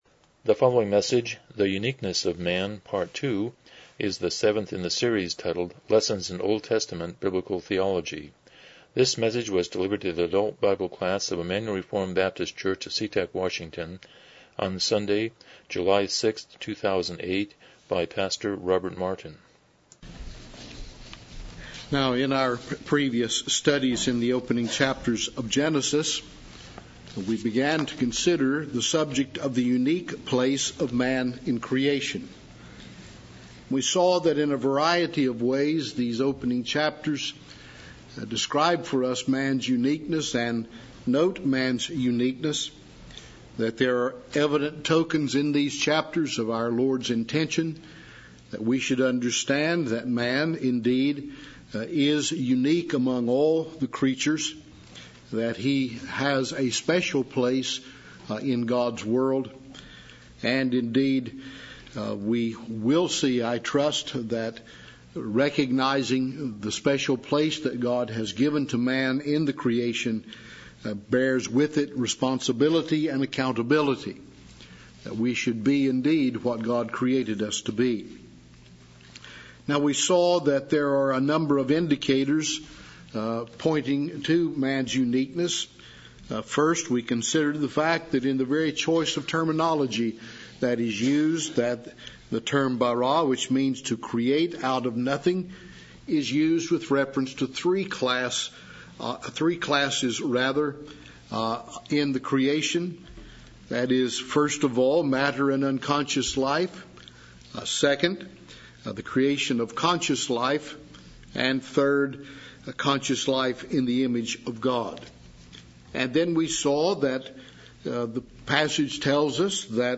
Lessons in OT Biblical Theology Service Type: Sunday School « 34 Chapter 3.3